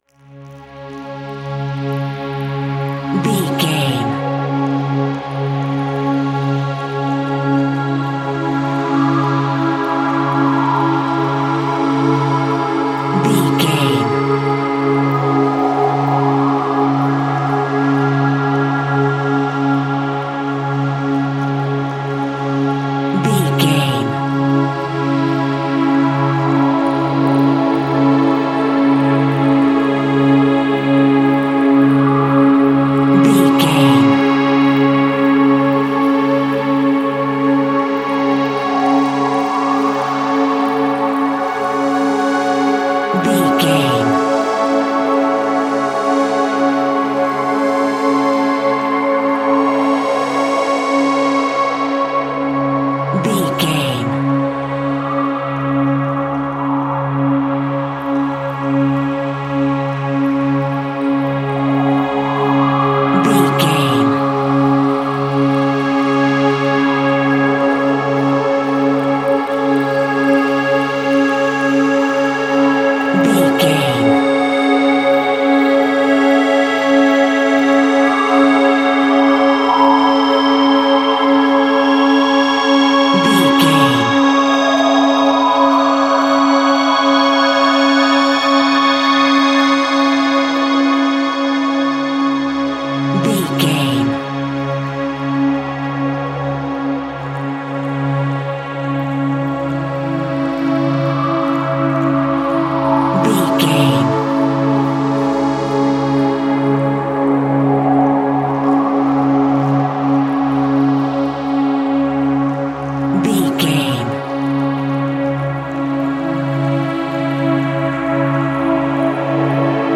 Thriller
Diminished
C#
Slow
scary
tension
ominous
dark
haunting
eerie
ethereal
synthesiser
strings
violin
Synth Pads
atmospheres